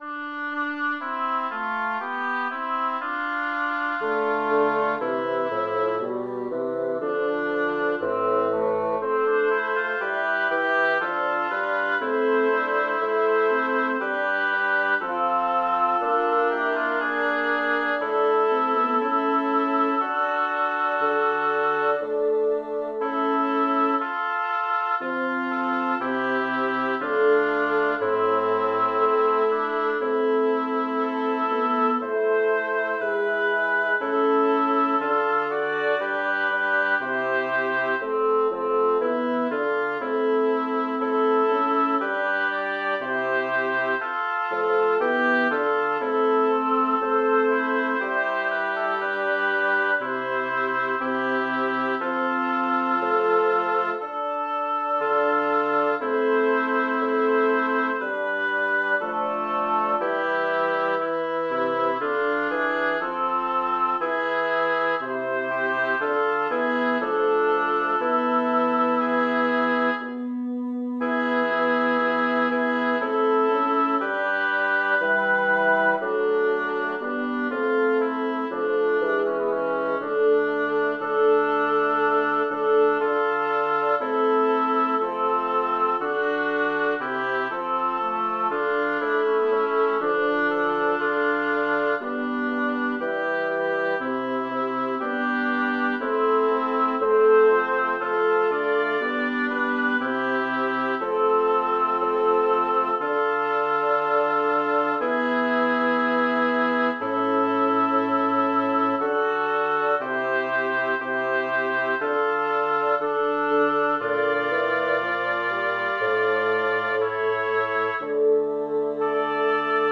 Title: Leggiadra pastorella Composer: Philippe Duc Lyricist: Scipione Gonzaga Number of voices: 4vv Voicing: SATB Genre: Secular, Madrigal
Language: Italian Instruments: A cappella